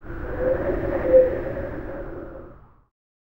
TUV NOISE 04.wav